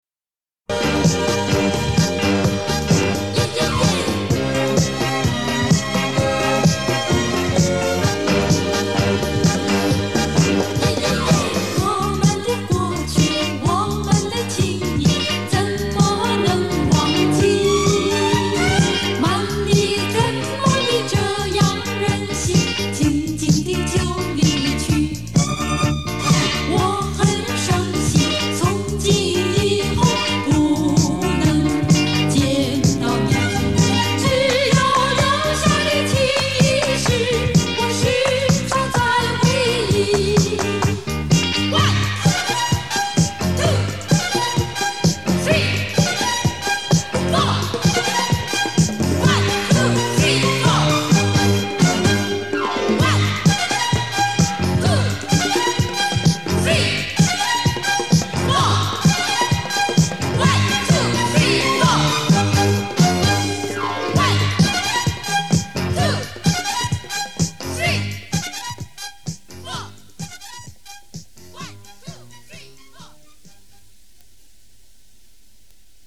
老磁带